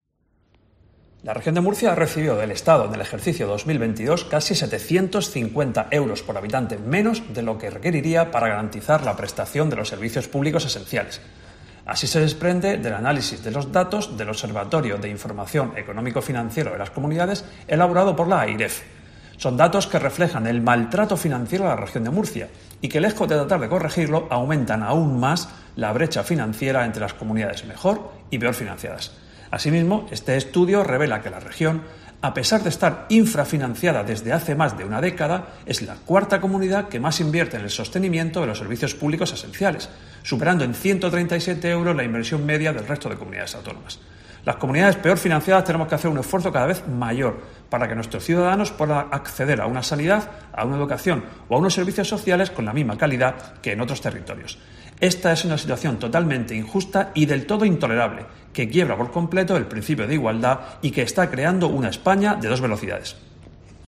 Luis Alberto Marín, consejero de Economía, Hacienda y Empresa